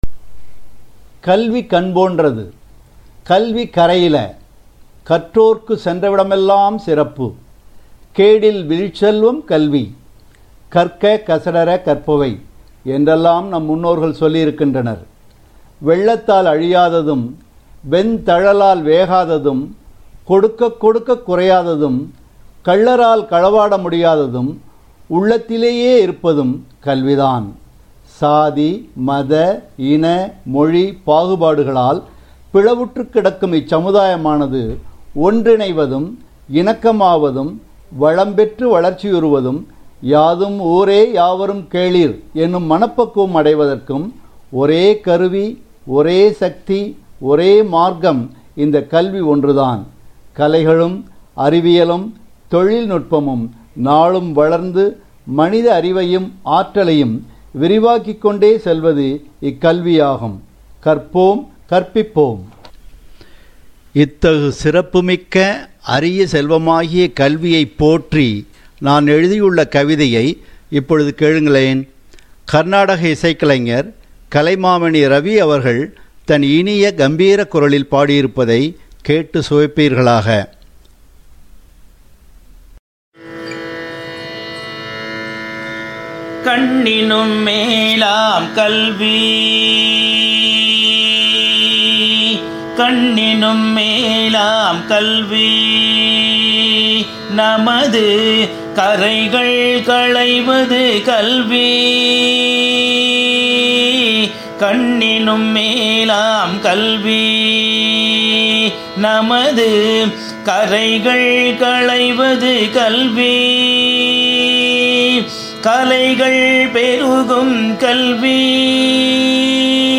இனிய , கம்பீரக் குரலில்
கர்நாடக இசைக்கலைஞர்